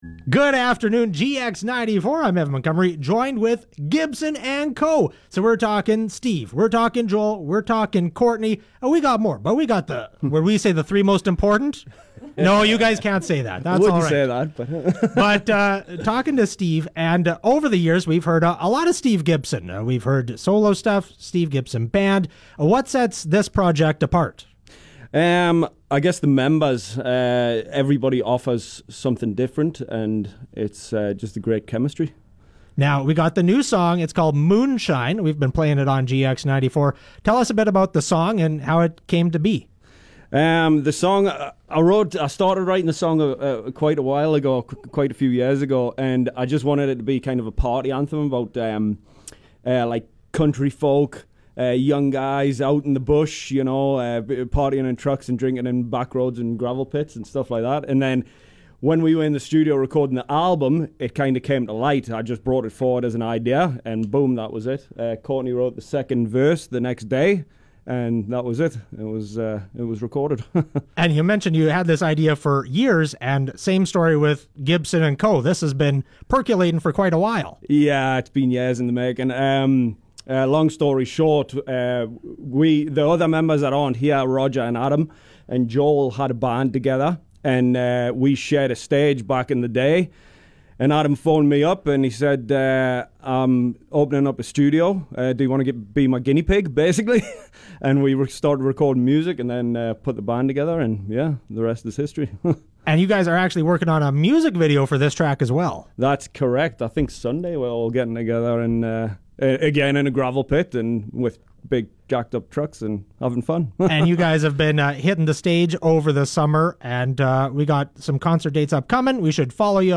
Interview with Gibson & Co